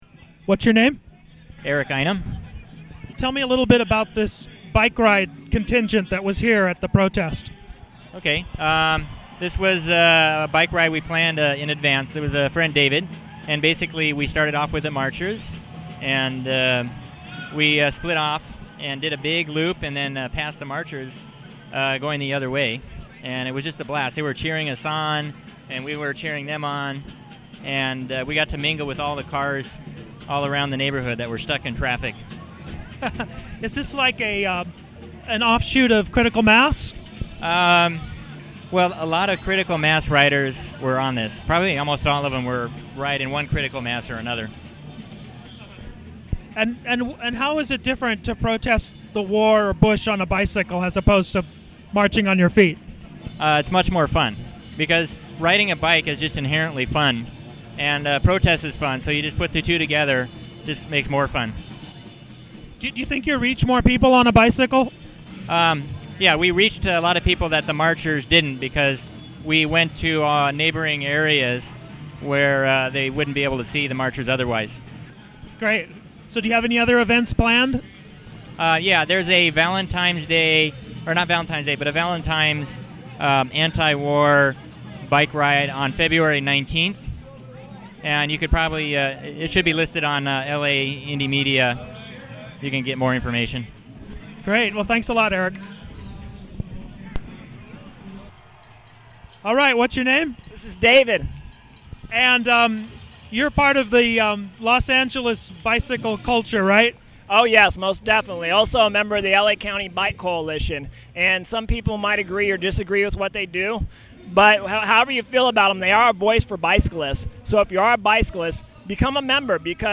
Uploaded is an mp3 file of two interviews with some of the proponents of the No War Bike Ride idea.